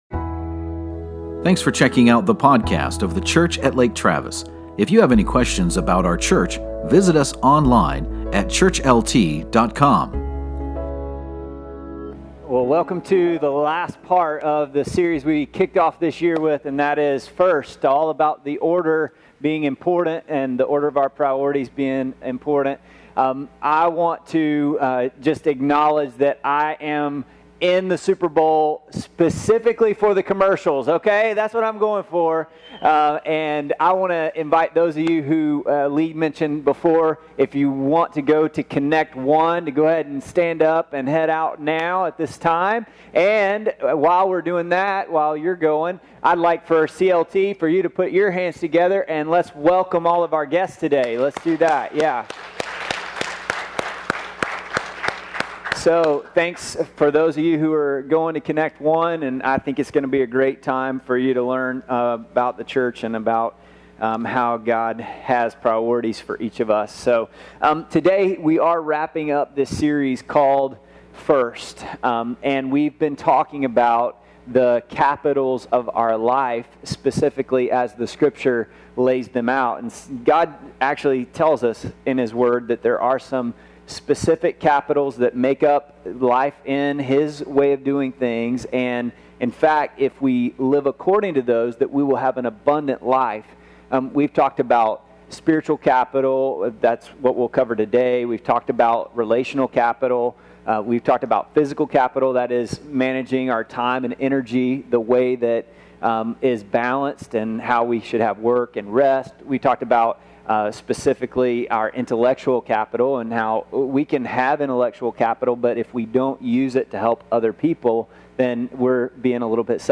But what are the big rocks and how do I put them into my life? In this message we uncover the concepts that will lead to the abundant life you have been searching for.